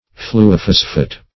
Search Result for " fluophosphate" : The Collaborative International Dictionary of English v.0.48: Fluophosphate \Flu`o*phos"phate\, n. [Fluo- + phosphate.]